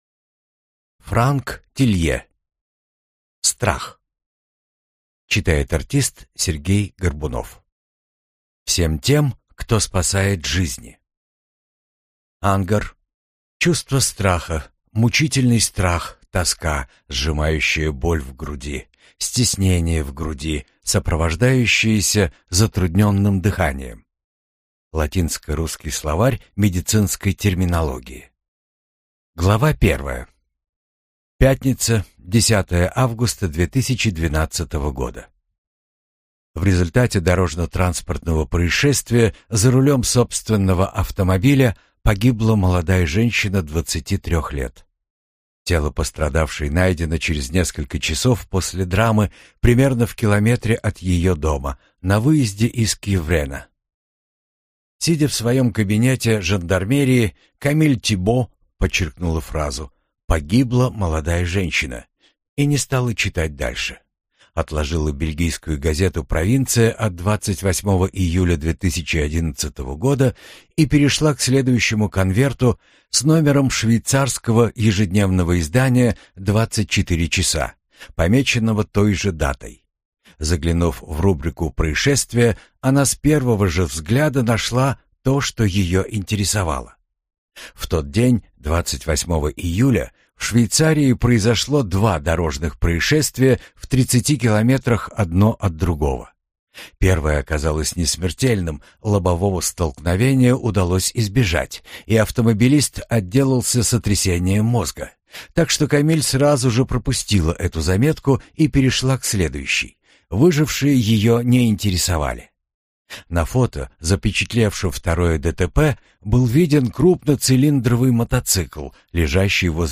Прослушать фрагмент аудиокниги Страх Франк Тилье Боевики Детективы Зарубежные детективы Произведений: 16 Скачать бесплатно книгу Скачать в MP3 Вы скачиваете фрагмент книги, предоставленный издательством